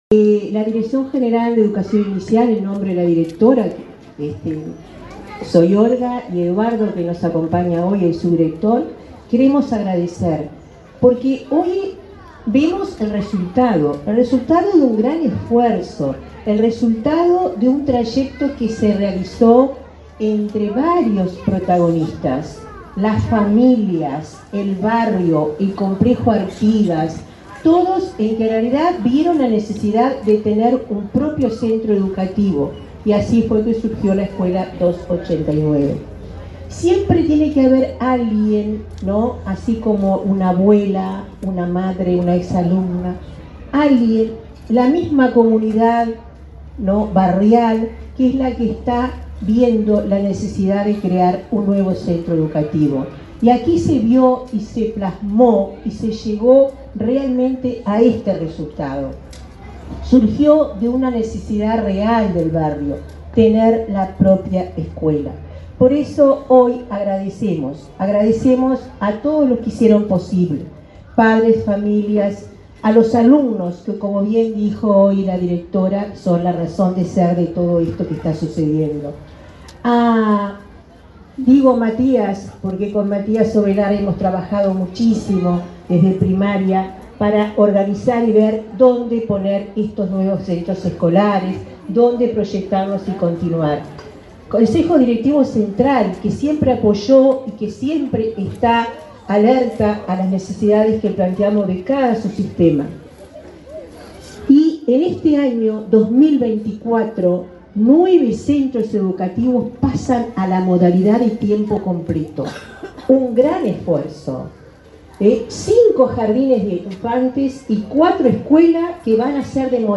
Palabras de autoridades en inauguración de ANEP en Montevideo